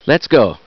Download Half Life Lets Go sound effect for free.